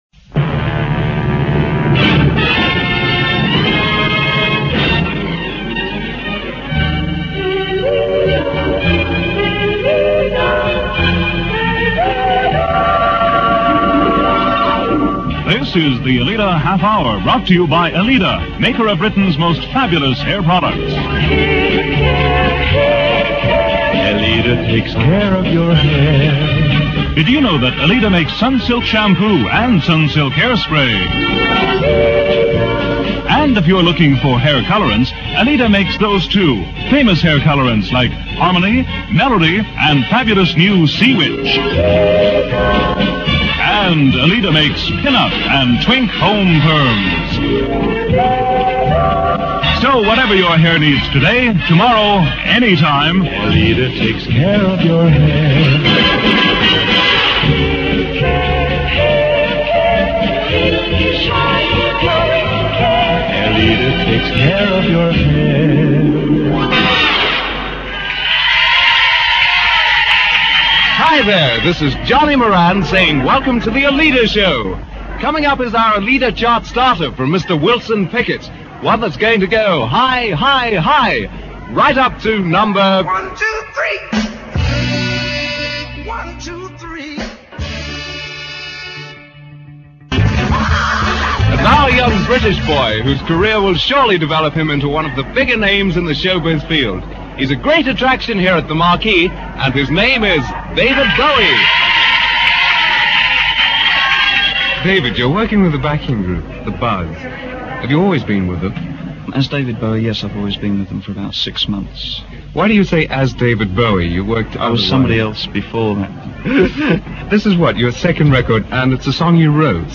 recorded at the Marquee Club in Soho and featuring an interview with the then little known David Bowie.